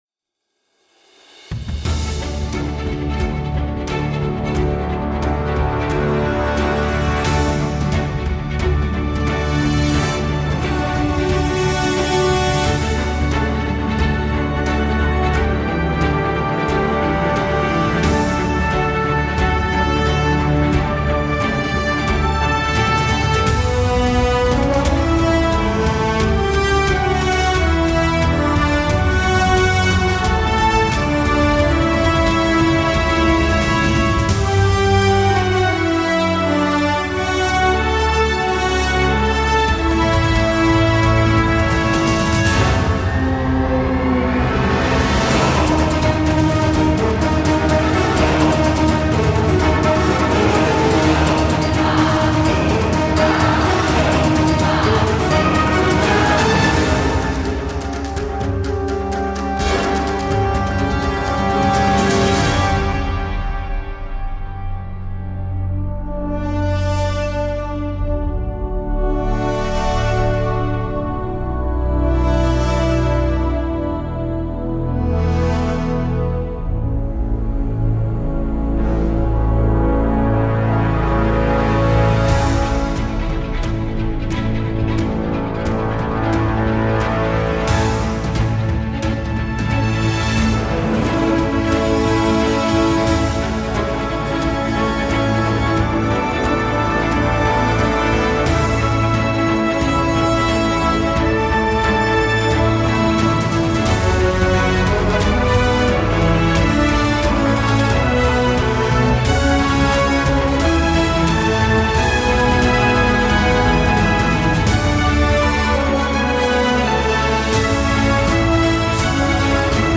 [推荐]铜管合奏 Symphony Series Brass Ensemble KONTAKT 1.3-音频fun
一个包含32件铜管乐器合奏的完整声音库，包括四个8人的乐器组
精准的铜管乐器之声
由顶级乐手使用32种铜管乐器在旧金山的圣保罗教堂中录制而成。
一个包含32件铜管乐器合奏的完整声音库，包括四个8人的乐器组： 小号、圆号、长号和大号。
四个麦克风混音，带有混音台界面